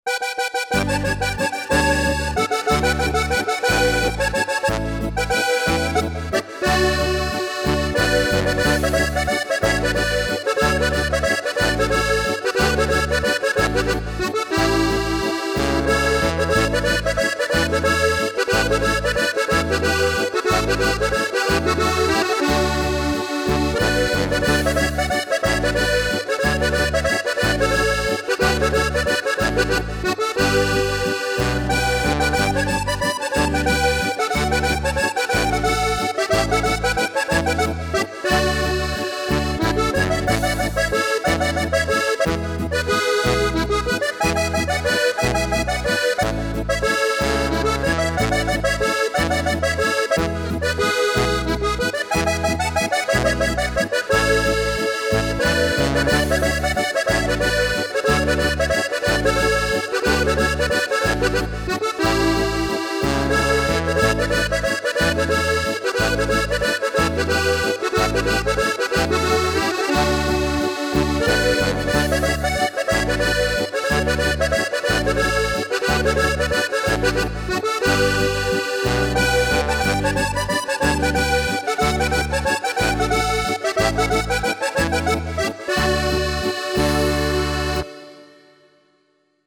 • Volksmusik
• Coverband
• Akkordeon